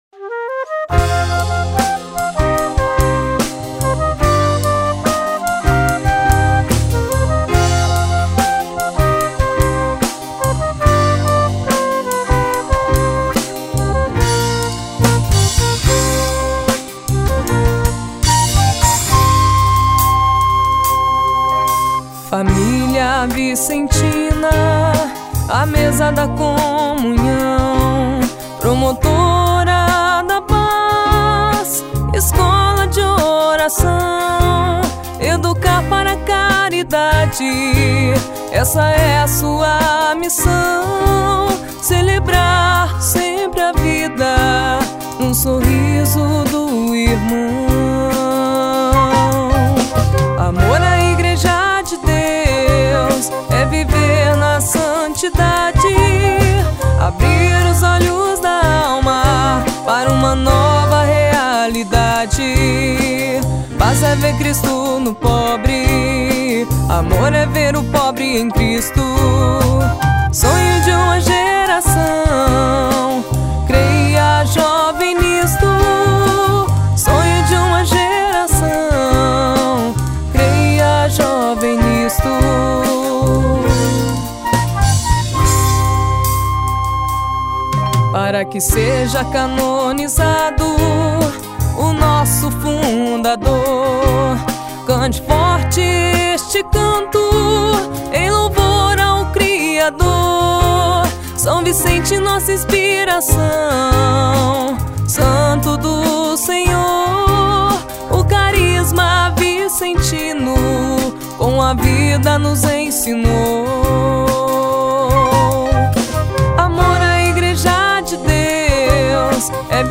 Recentemente, ao se encontrar com o amigo que era dono do estúdio que gravou a composição, ele conseguiu recuperar o áudioo, que tem circulado nas redes sociais.